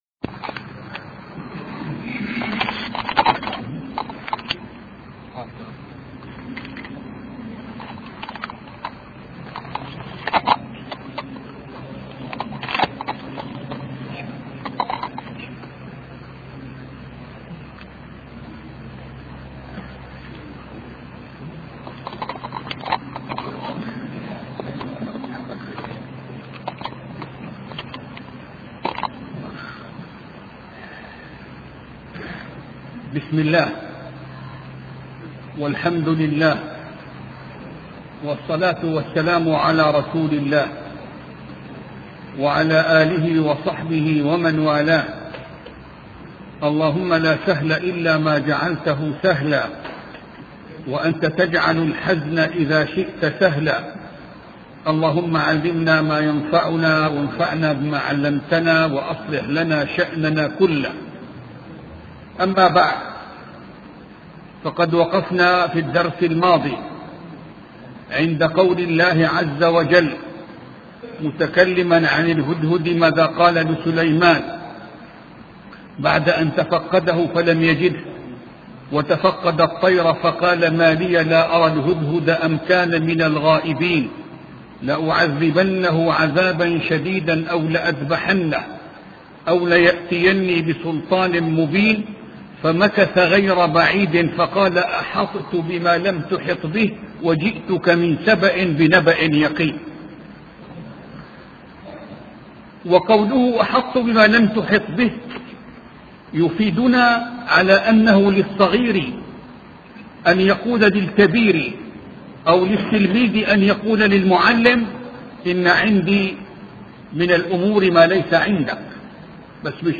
سلسلة محاضرات في قصة سليمان علية السلام